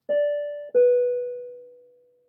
767 Cabin Chime